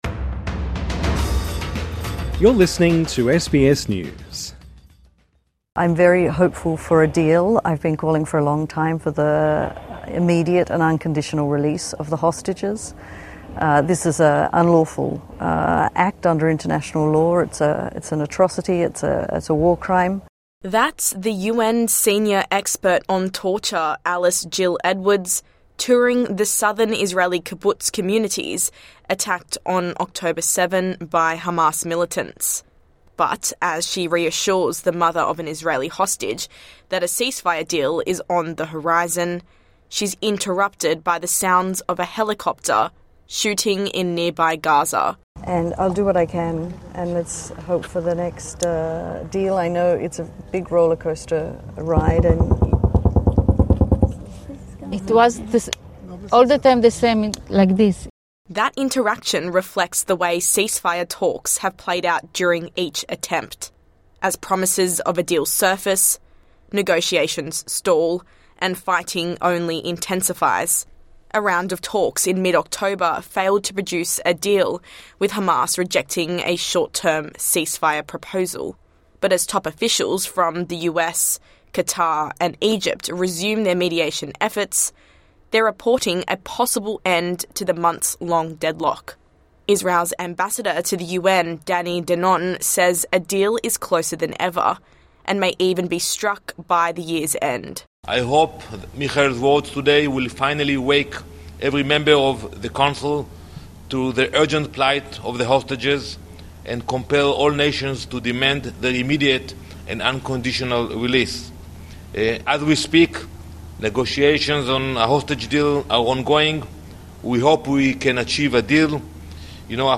(Shooting sounds)